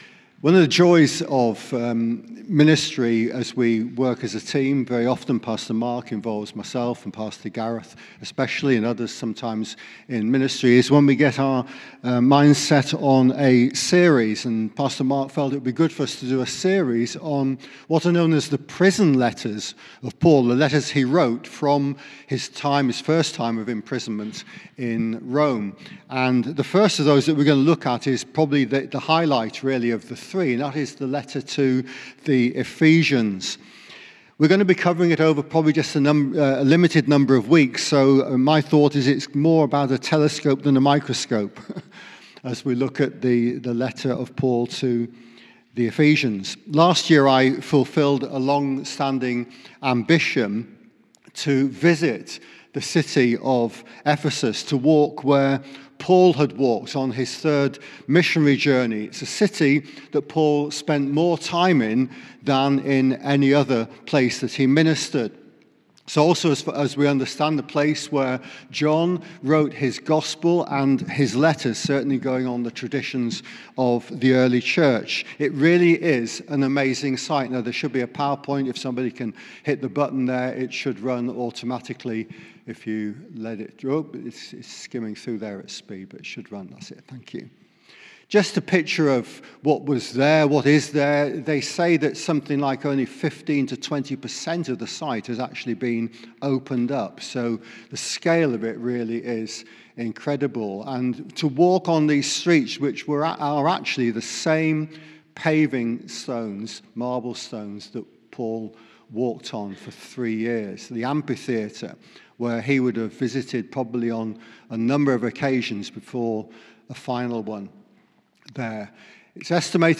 Sermon - The Book of Ephesians Part1 - Ephesians 1:4